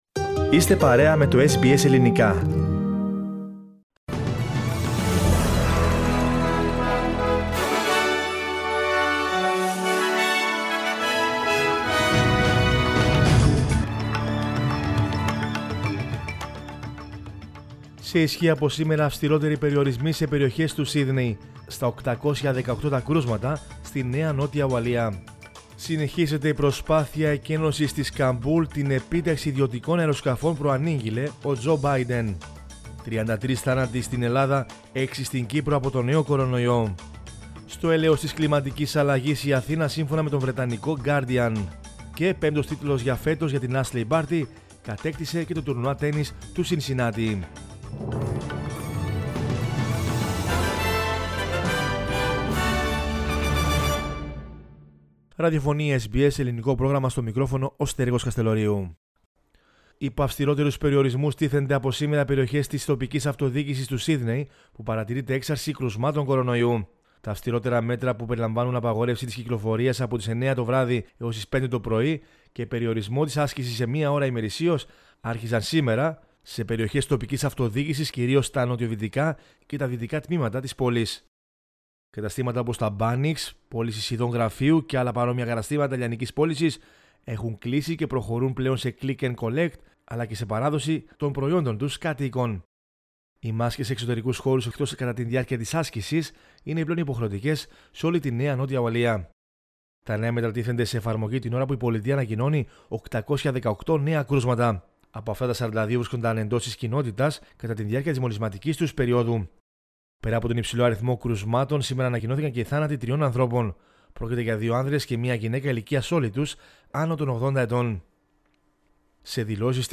News in Greek from Australia, Greece, Cyprus and the world is the news bulletin of Monday 23 August 2021.